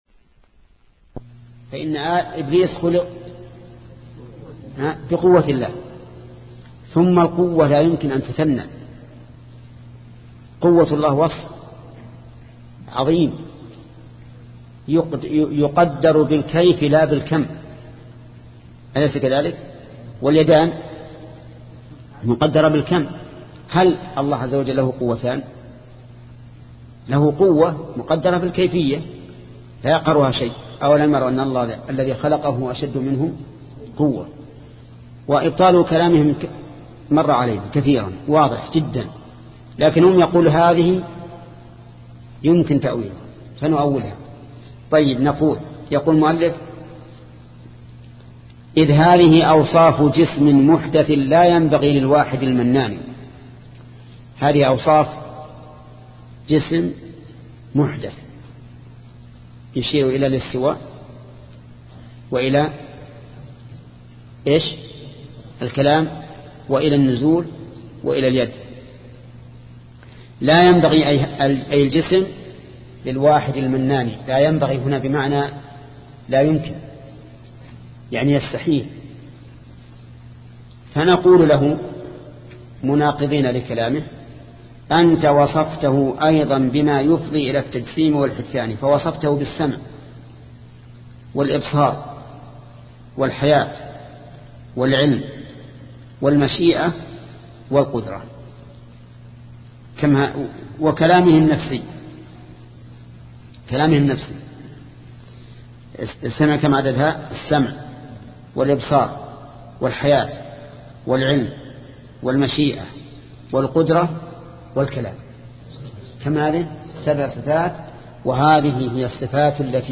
شبكة المعرفة الإسلامية | الدروس | التعليق على القصيدة النونية 24 |محمد بن صالح العثيمين